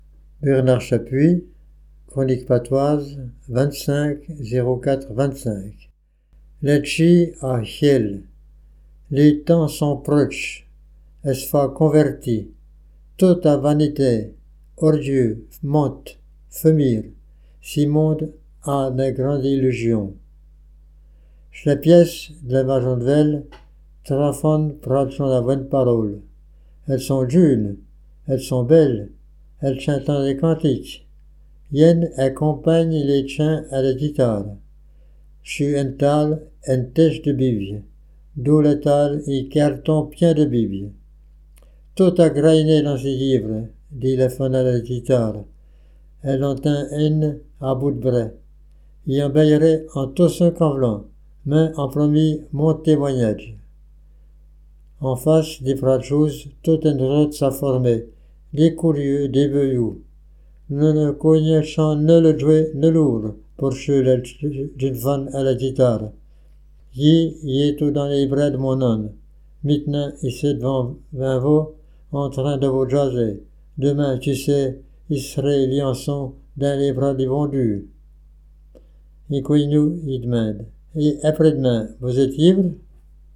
Patois Jurassien